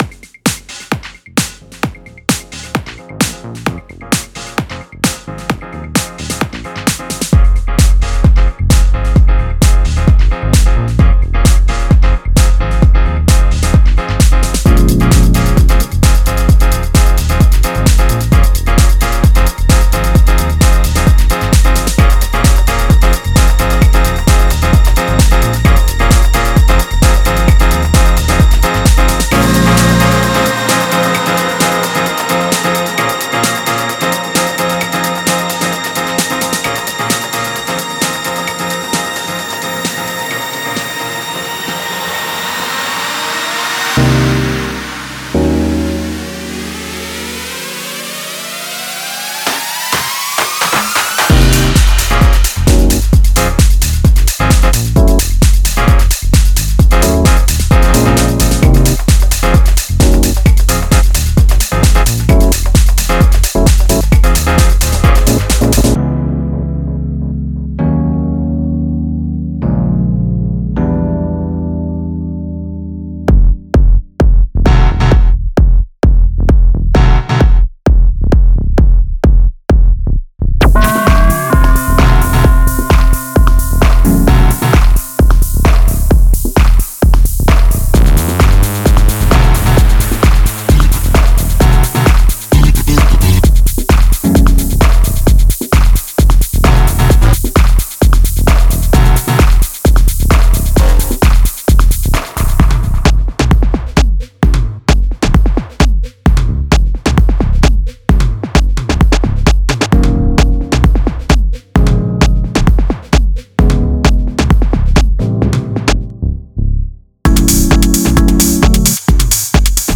これは、クラシックハウスのソウルフルな心と、世界を席巻したレイブの無限のエネルギーが生んだ音のラブチャイルドです。
メインイベントは、エウフォリアを叫ぶアナログレイブリードループです。
シルクのように滑らかなクラシックハウスストリングスの膨大なフォルダが、必要なクラス感とドラマを加えます。
基盤は、深くグルーヴィーなアナログベースとリッチなアナログコードによって支えられ、聴く者の頭を即座に揺らします。
デモサウンドはコチラ↓
Genre:House
180 Piano Loops (House Pianos & Electric)
180 Synth Loops (Rave Synths & Chords)
240 Analog Bass Loops (incl.Sidechain)